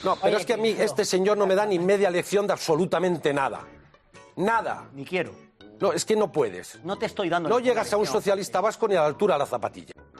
Patxi López hablando durante el primer debate electoral de la Cadena COPE